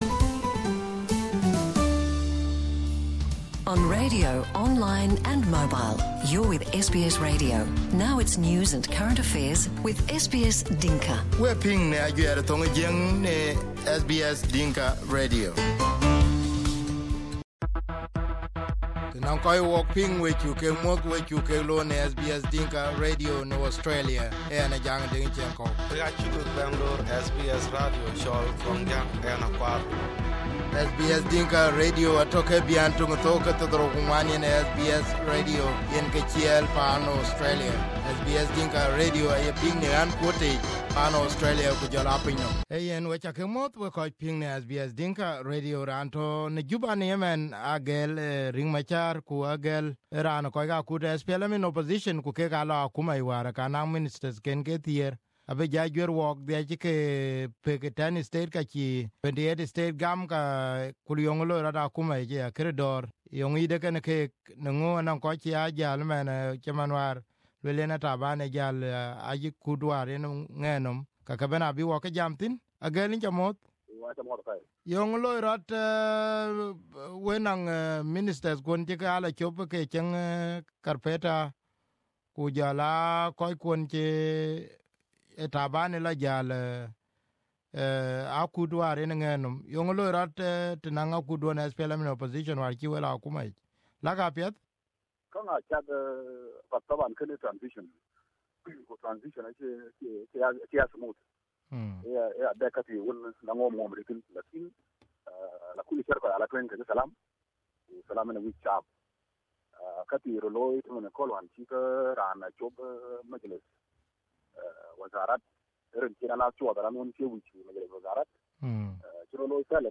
Here is his interview on SBS Dinka Radio